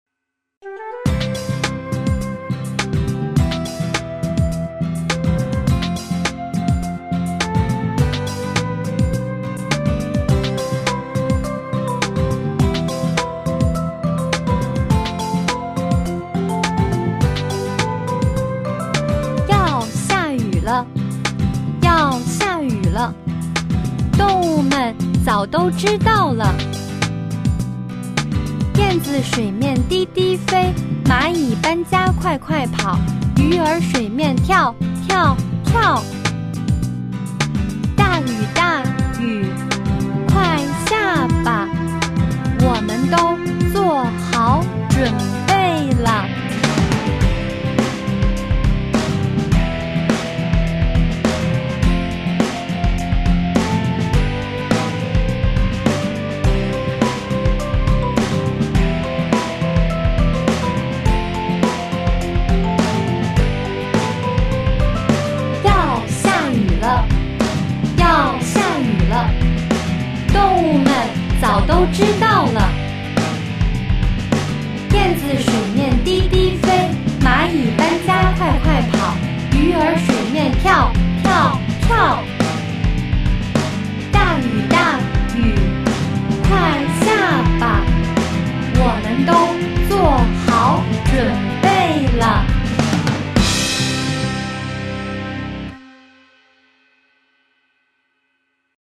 Cùng hát nhé